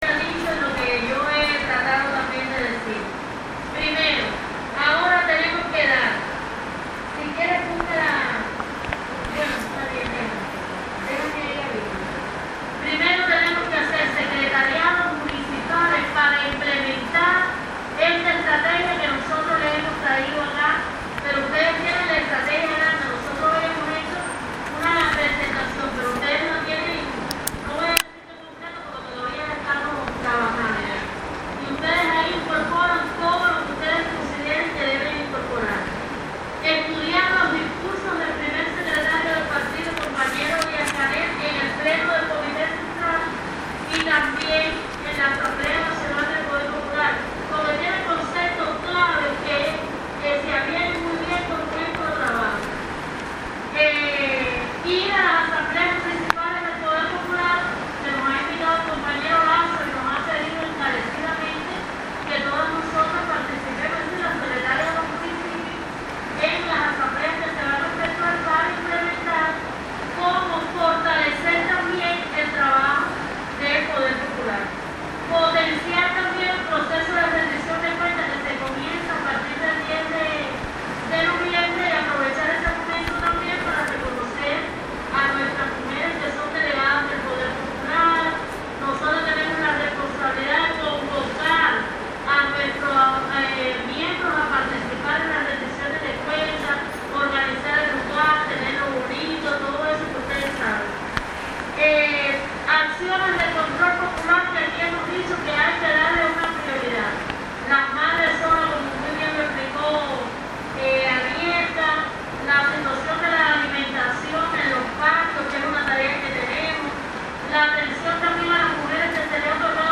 Palabras de Teresa Amarelle Boué, integrante del Buró Político del Partido y Secretaria general de la Federación de Mujeres Cubanas.